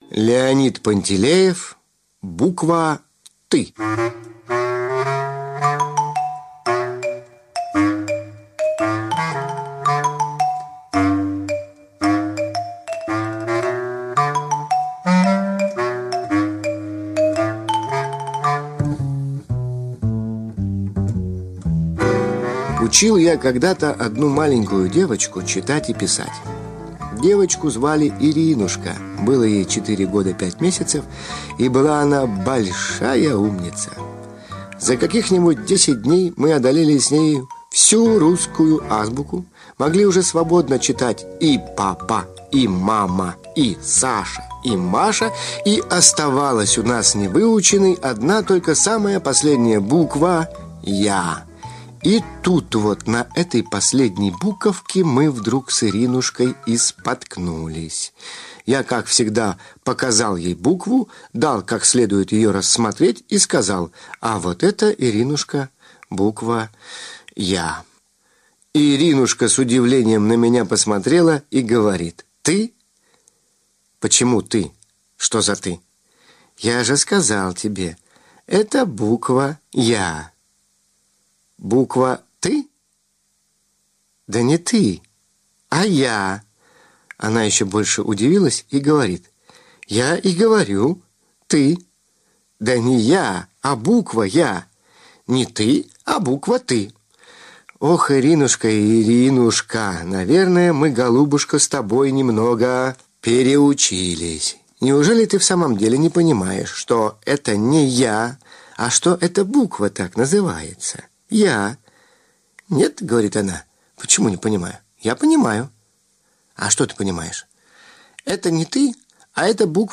Аудиорассказ «Буква ты»
Озвучка не очень ( 😓)